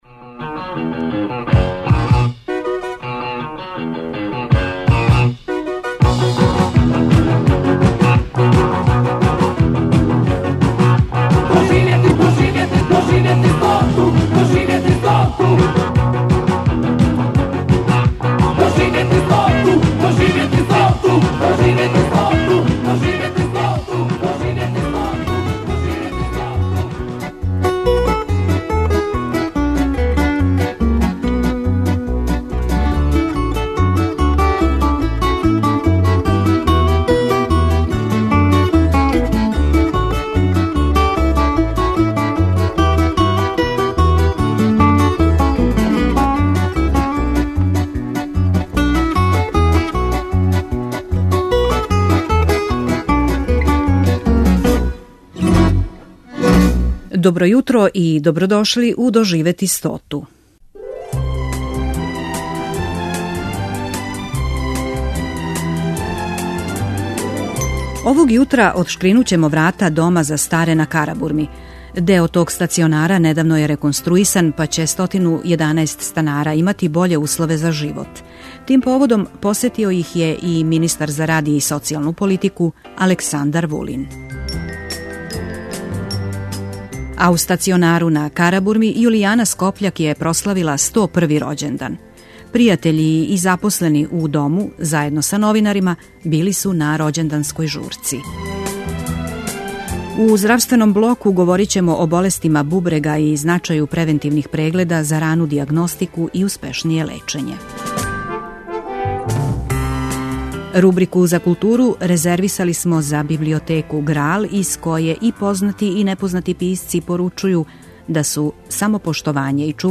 Реновиране просторије Дома на Карабурми обишао је министар за рад социјалну политику Александар Вулин и разговарао са запосленима и корисницима те установе.
Емисија "Доживети стоту" Првог програма Радио Београда већ двадесет четири године доноси интервјуе и репортаже посвећене старијој популацији.